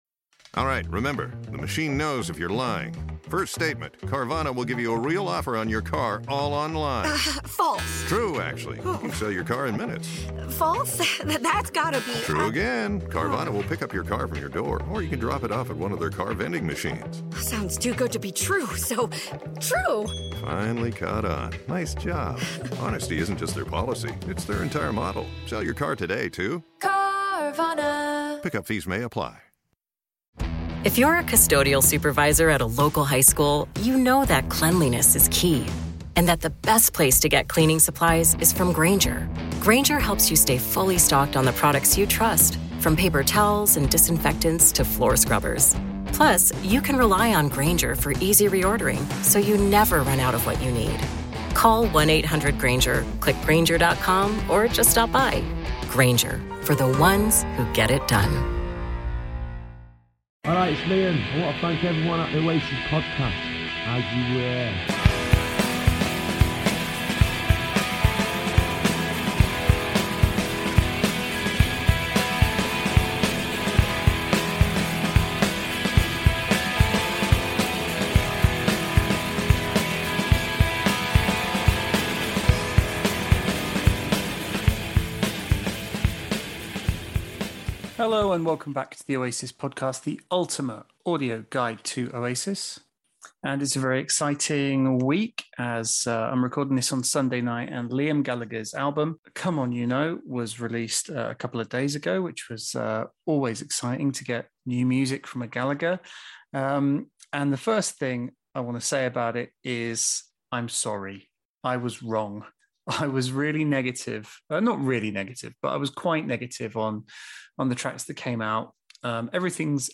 Todays guest is ... no-one!